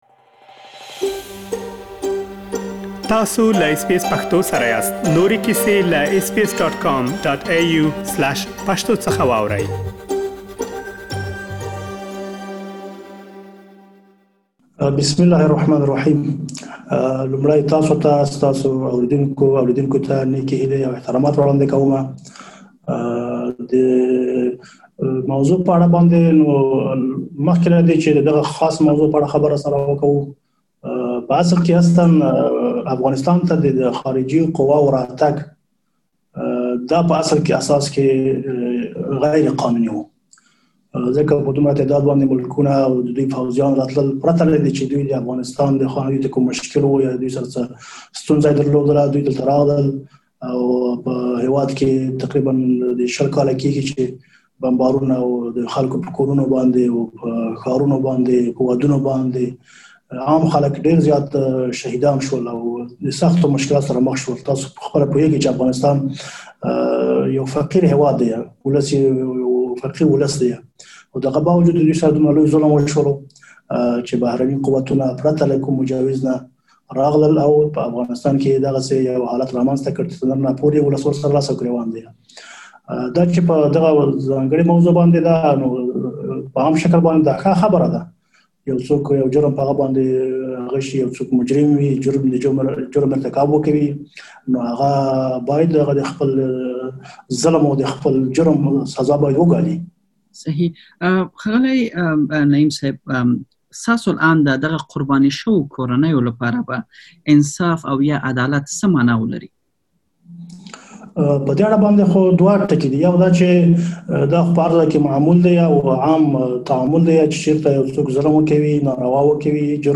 ځانګړې مرکه کړې.